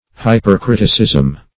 Search Result for " hypercriticism" : The Collaborative International Dictionary of English v.0.48: Hypercriticism \Hy`per*crit"i*cism\, n. Excessive criticism, or unjust severity or rigor of criticism; zoilism.
hypercriticism.mp3